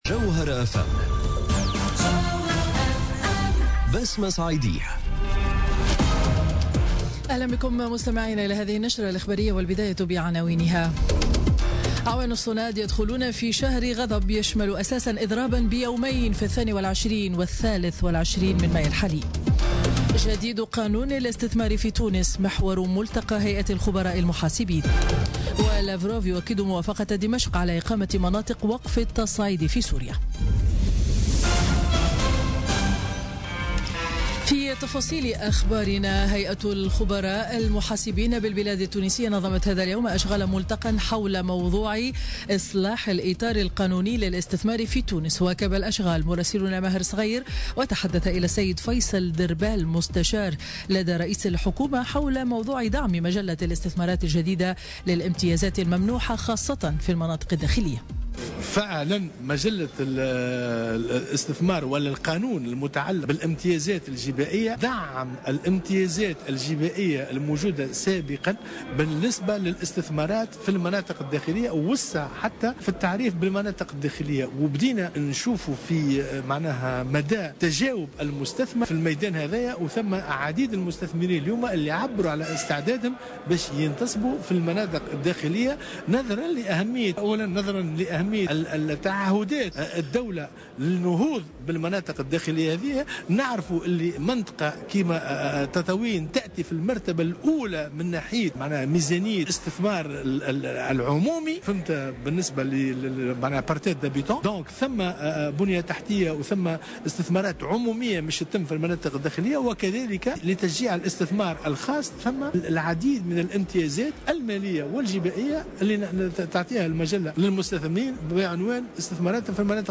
نشرة أخبار منتصف النهار ليوم الخميس 4 ماي 2017